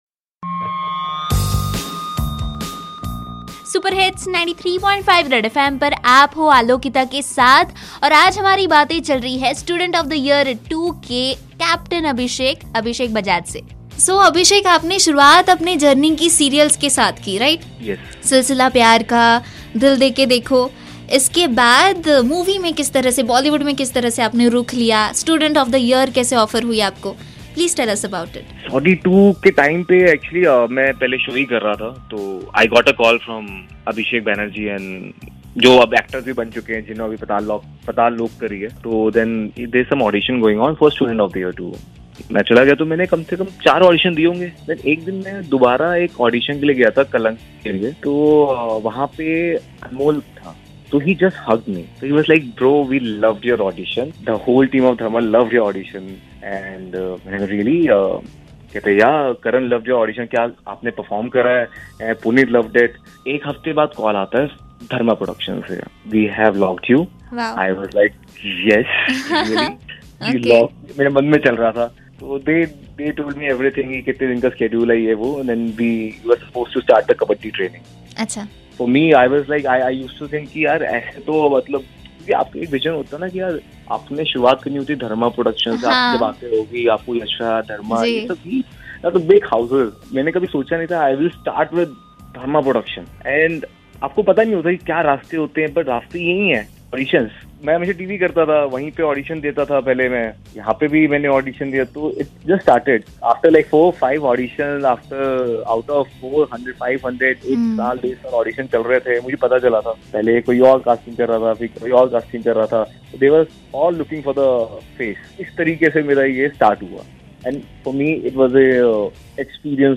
ABHISHEK BAJAJ INTERVIEW - 2